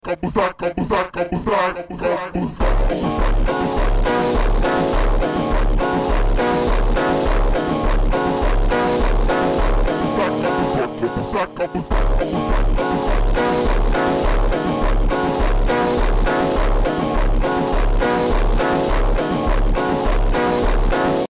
(Super Slowed)